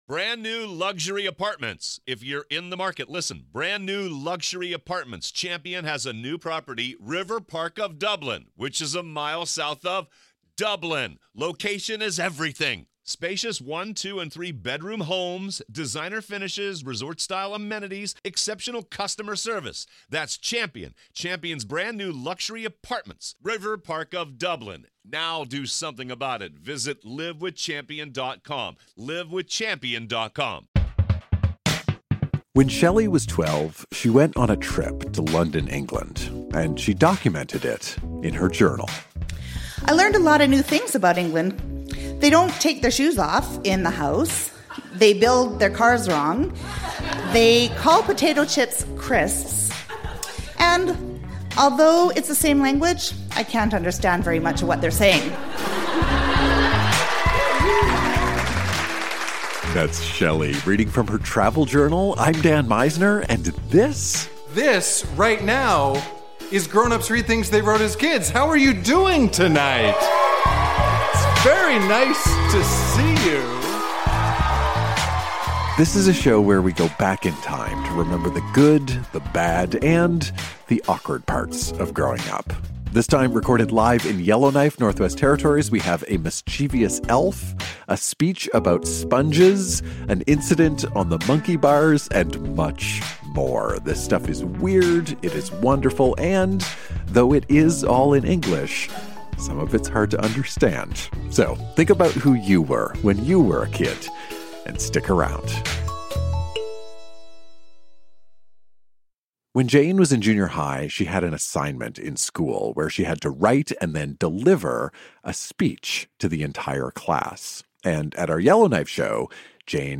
Recorded live at the Black Knight in Yellowknife.